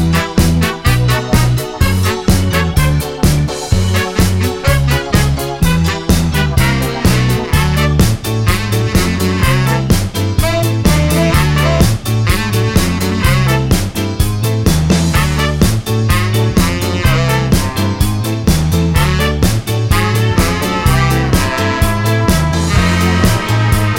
no Backing Vocals Ska 3:36 Buy £1.50